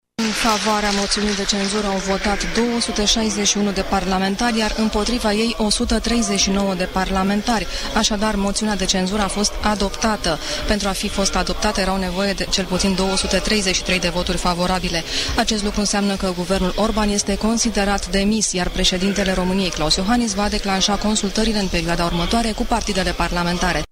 De la Palatul Parlamentului relatează